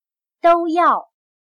都要/dōu yào/Ambos